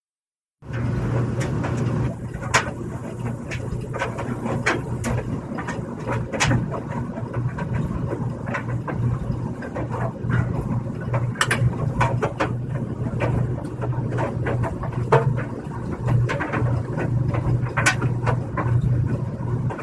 弗利大学的声音 " 门外的烘干机
描述：烘干机翻滚